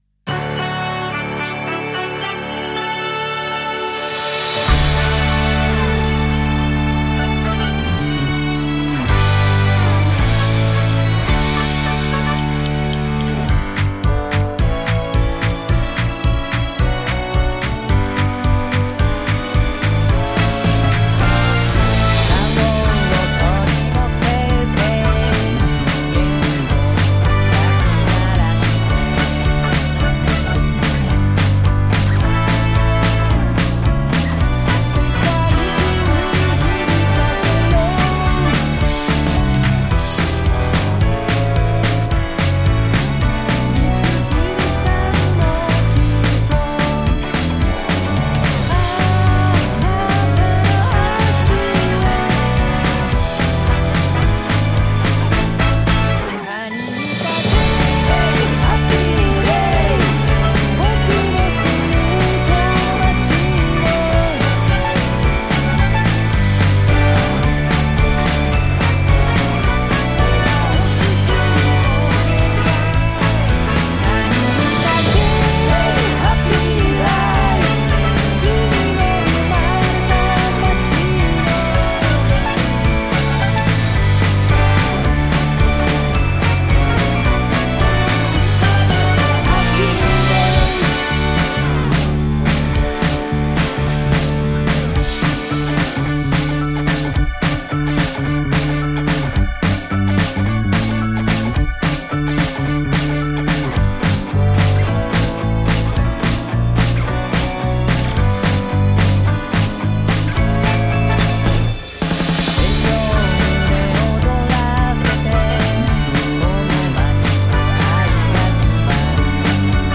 カラオケver